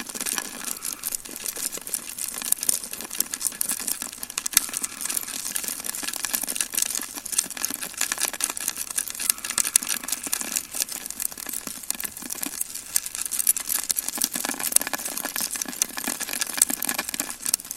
The sound of a female carpenter bee (Xylocopa virginica, the “Virginia wood-cutter”) chewing wood fibers at the end of her tunnel inside a piece of exposed wood in a barn. She’s chewing away with her sharp mandibles, making a tubular nest for her eggs.
carpenterbee.mp3